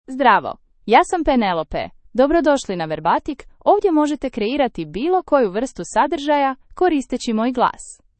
Penelope — Female Croatian (Croatia) AI Voice | TTS, Voice Cloning & Video | Verbatik AI
FemaleCroatian (Croatia)
Penelope is a female AI voice for Croatian (Croatia).
Voice sample
Penelope delivers clear pronunciation with authentic Croatia Croatian intonation, making your content sound professionally produced.